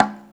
51 CONGA.wav